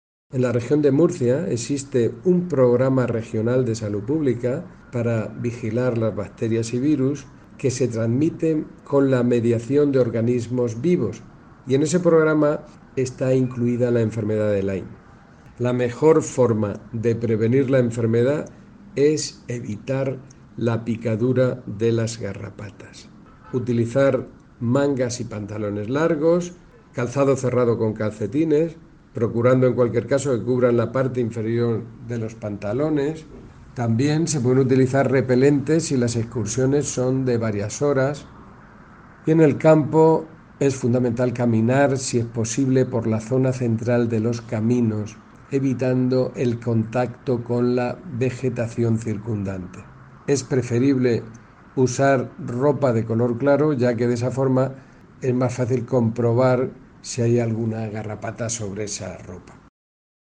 Declaraciones del director general de Salud Pública, José Jesús Guillén, sobre las medidas que hay que tomar a fin de evitar la Enfermedad de Lyme [mp3]